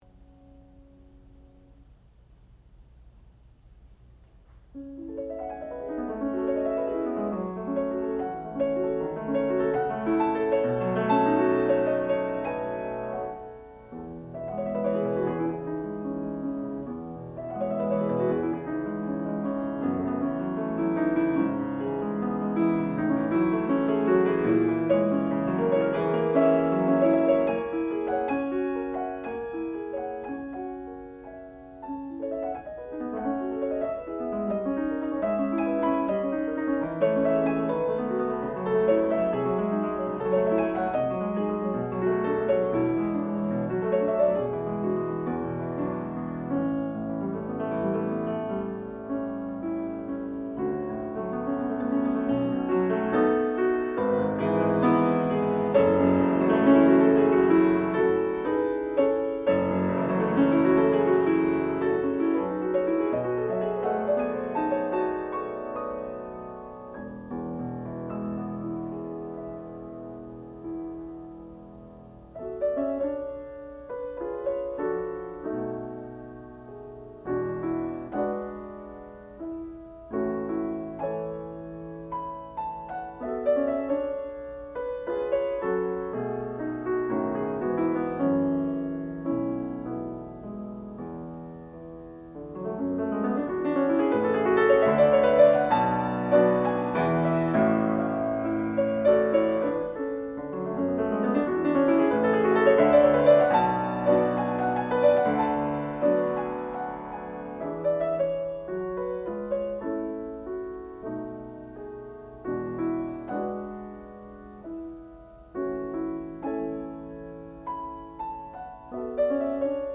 sound recording-musical
classical music
Junior Recital
piano